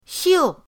xiu4.mp3